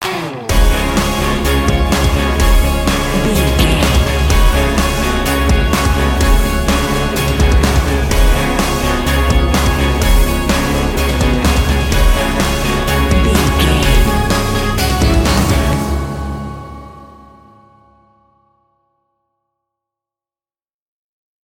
Epic / Action
In-crescendo
Phrygian
C#
drums
electric guitar
bass guitar
Sports Rock
hard rock
metal
aggressive
energetic
intense
nu metal
alternative metal